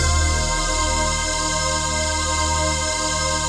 VOICEPAD16-LR.wav